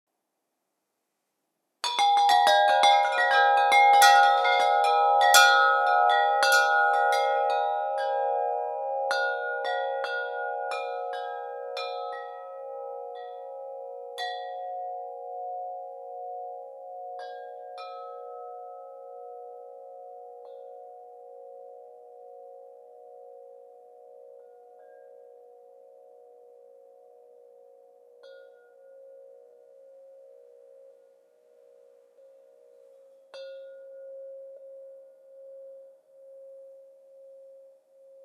Diese Chimes zeichnen sich durch eine besonders gute Resonanz und einen langen Nachhall aus. Sie sind auf die Basis A4/a' 432 Hz gestimmt. Genießen Sie den weichen und beruhigenden Klang.
Ihr magischer Klang erfüllt die ganze Umgebung und lädt zum Träumen ein.